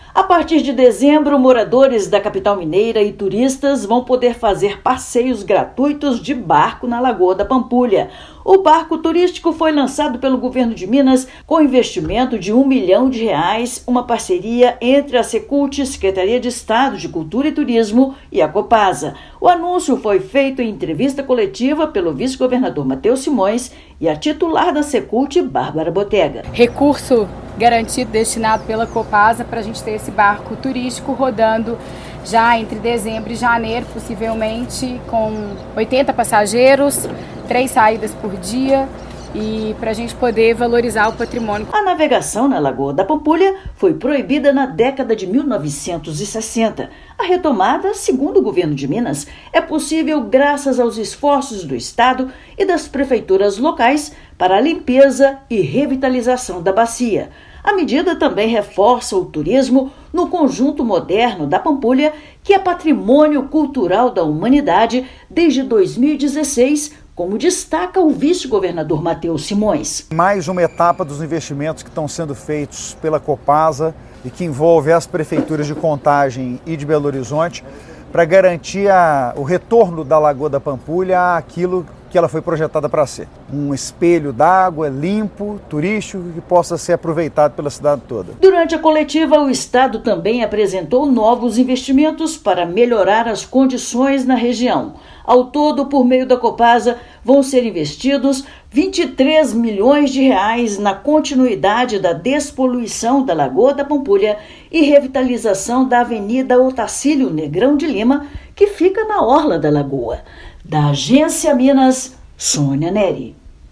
[RÁDIO] Governo de Minas anuncia barco turístico para passeios na Lagoa da Pampulha
Estado vai investir R$ 1 milhão na nova atração de Belo Horizonte, que deve começar a navegar pelo cartão-postal entre dezembro e janeiro, gratuitamente. Ouça matéria de rádio.